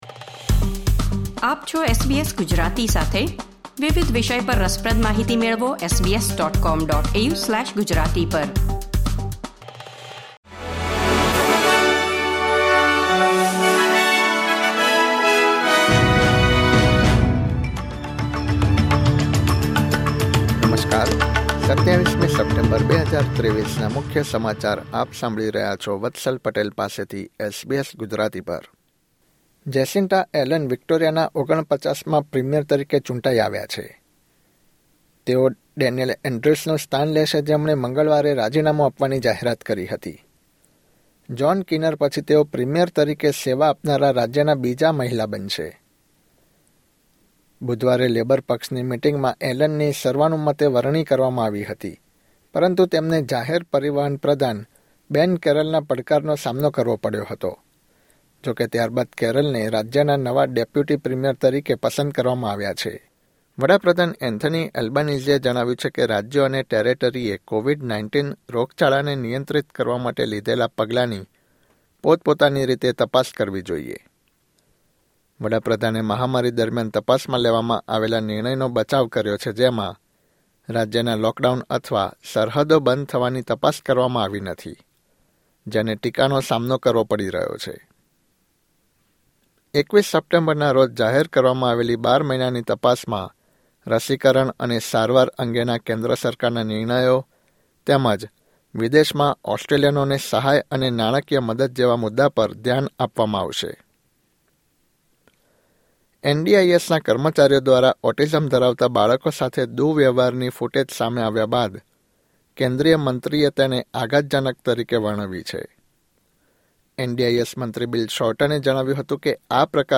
SBS Gujarati News Bulletin 27 September 2023